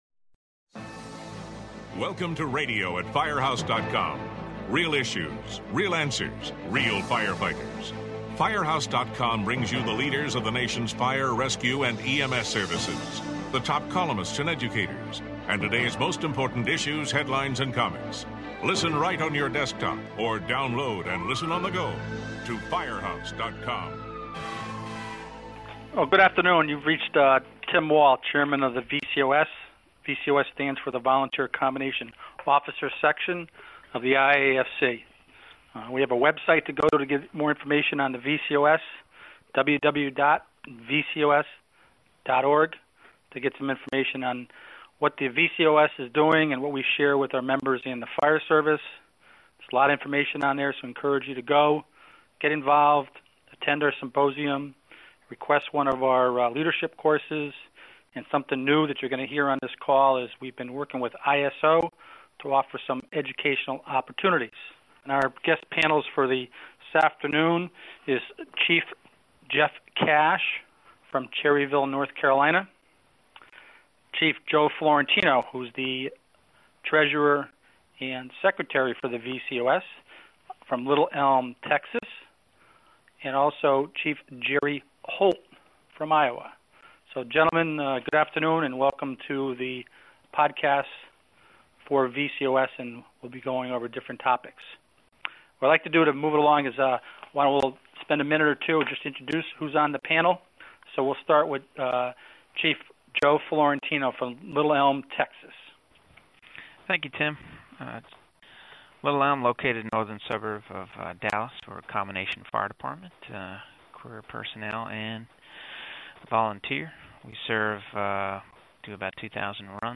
The guests are all members of the Volunteer and Combination Officer Section (VCOS) of the International Association of Fire Chiefs (IAFC.) The panels members talk about issues including response safety, the proper use of personal protective equipment (PPE) and fitness and wellness programs.